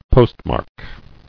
[post·mark]